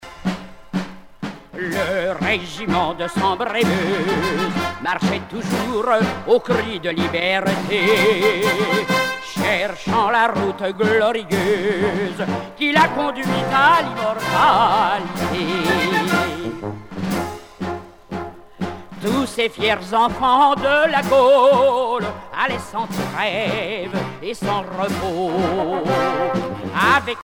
gestuel : à marcher
Genre strophique
Pièce musicale éditée